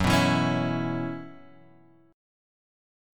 F#7sus4 chord {2 2 2 x 2 0} chord